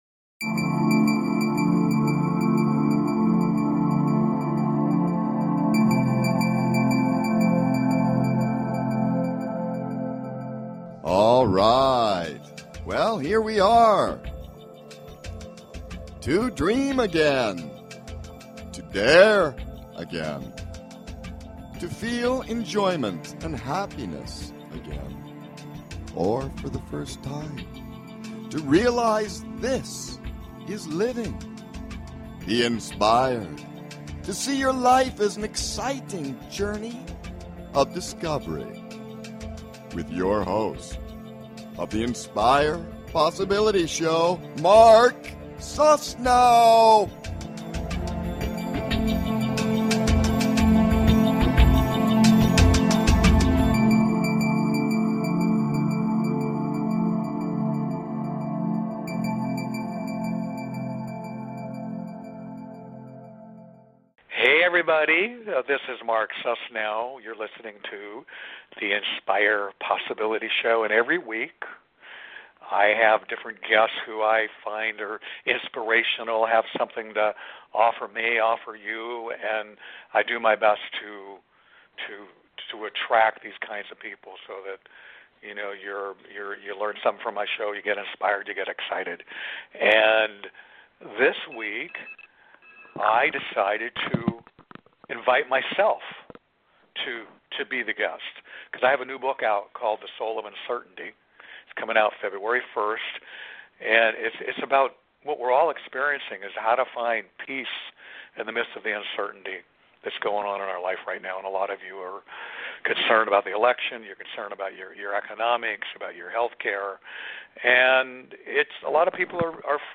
Talk Show Episode
interviews